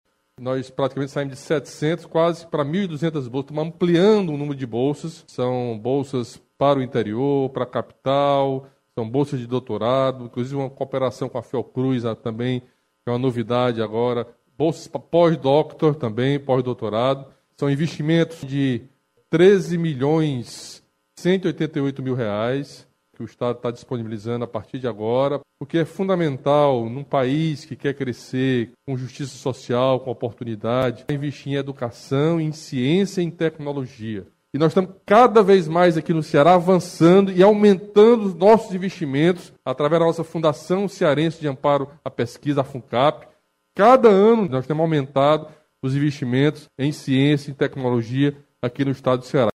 O anúncio foi feito pelo governador do Camilo Santana, na manhã desta quarta-feira (04), durante live no Palácio da Abolição.
O governador Camilo Santana destacou a importância dos investimentos em bolsas de pesquisa e em Ciência e Tecnologia para trazer desenvolvimento para o Ceará.